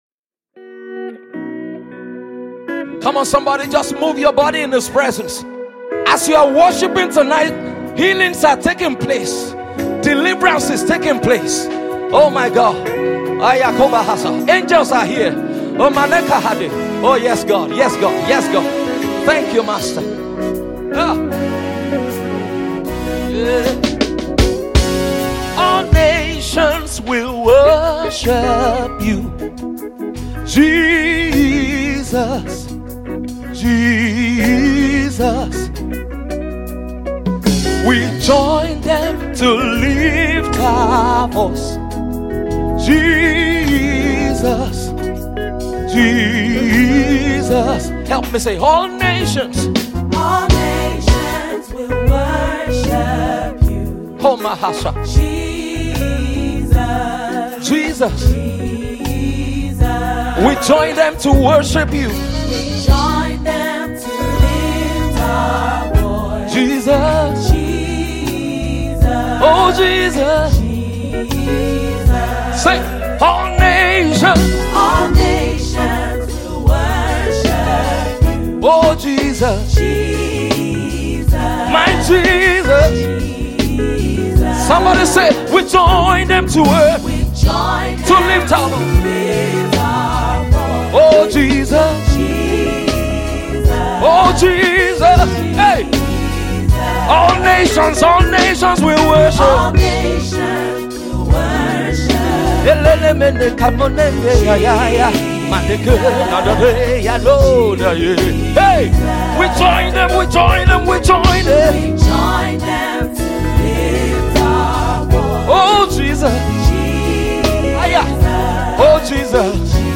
worship single
recorded live in Dallas, Texas, USA